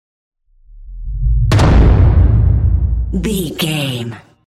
Dramatic hit explosion trailer
Sound Effects
Atonal
heavy
intense
dark
aggressive